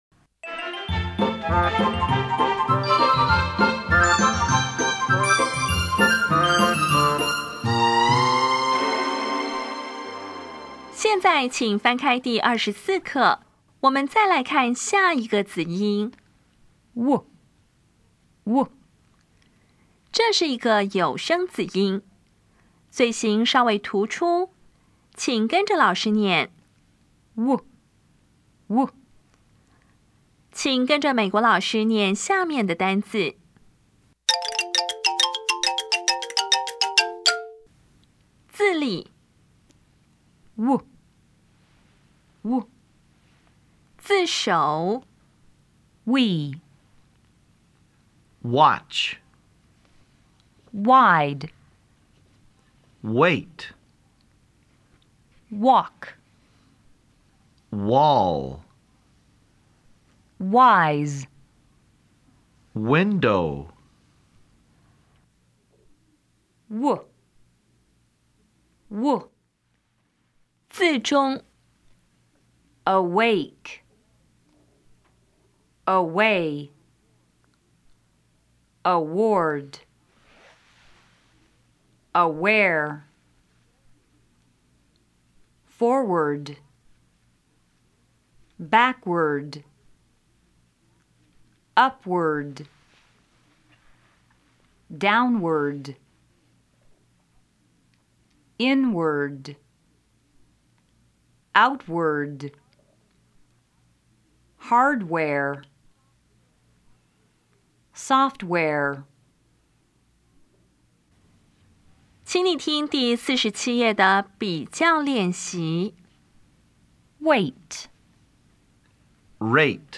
当前位置：Home 英语教材 KK 音标发音 子音部分-2: 有声子音 [w]
音标讲解第二十四课
[wɑtʃ]
[ˋwɪndo]
比较[w] 与 [r]       [w](有声) [r](有声/卷舌)